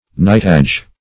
Knightage \Knight"age\, n. The body of knights, taken collectively.